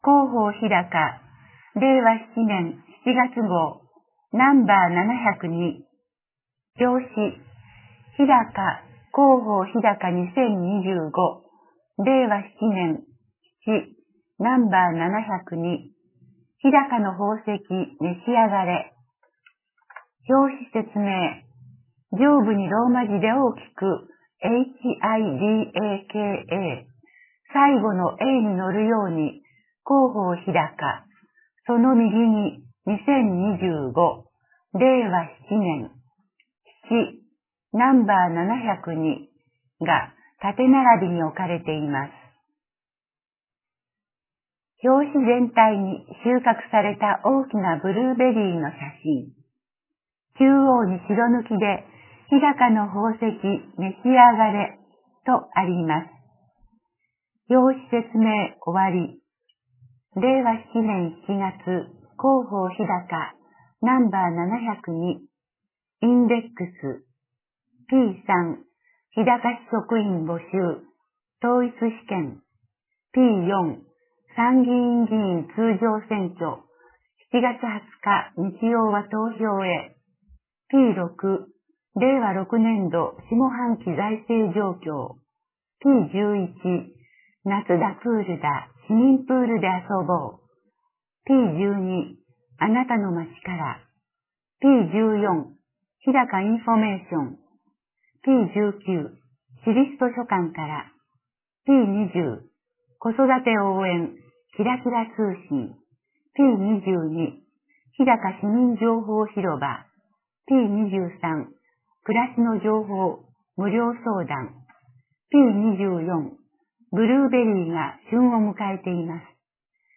朗読ボランティアグループ「日高もくせいの会」の皆さんのご協力により、「声の広報ひだか」を発行しています。